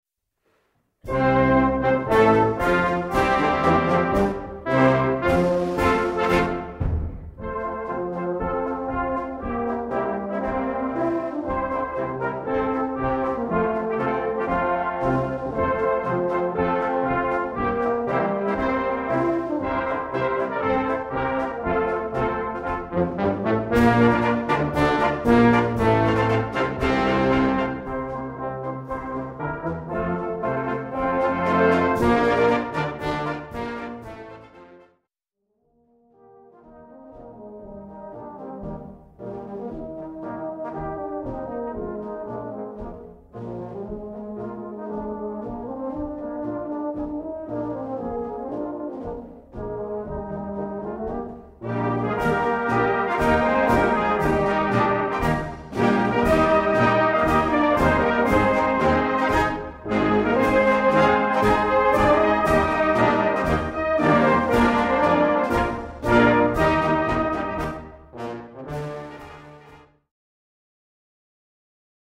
Gattung: Marsch
A5-Quer Besetzung: Blasorchester PDF